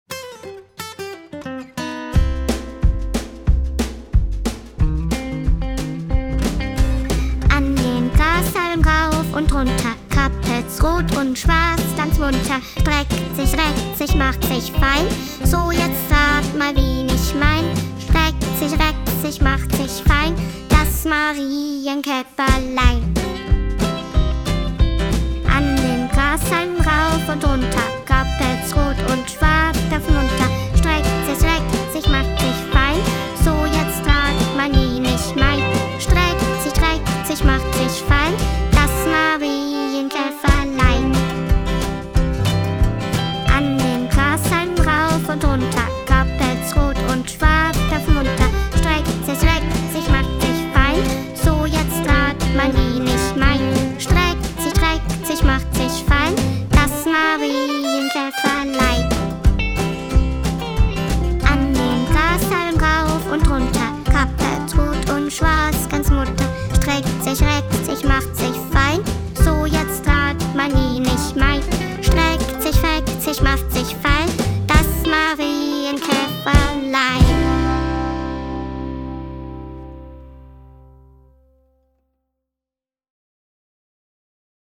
Auf der Wiese - Das Marienkäferlein - Sprechstück und Begleitung (Bordun und Pentatonik)
Auf der Wiese - Das Marienkäferleins - Einspielung des Sprechstücks mit Begleitung